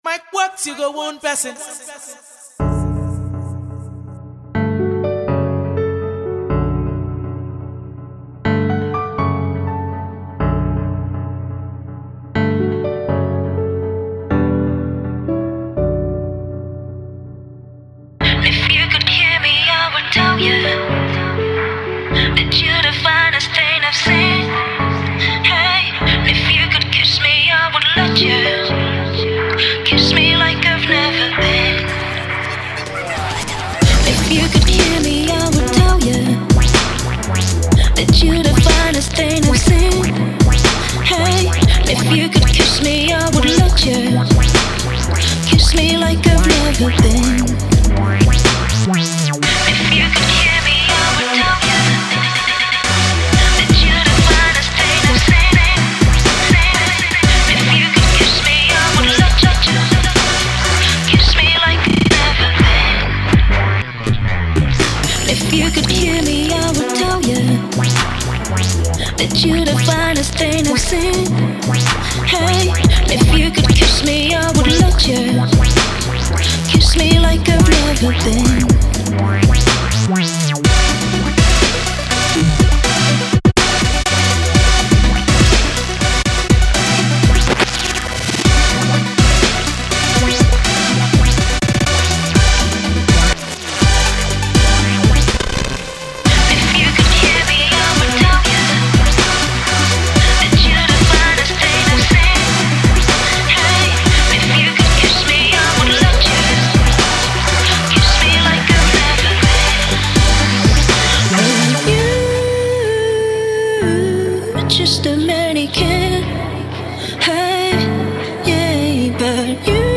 electronic mixes